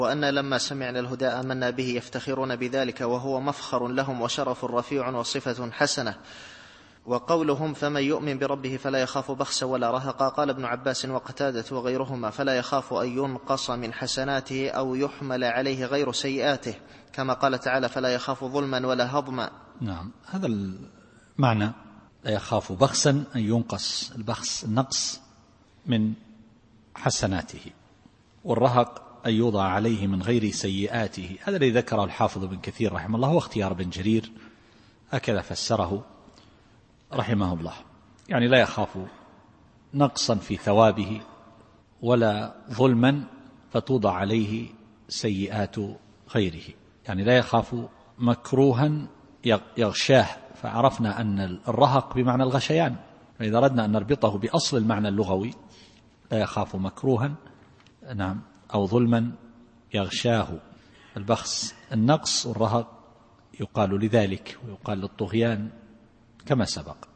التفسير الصوتي [الجن / 13]